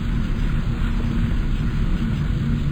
thrust.wav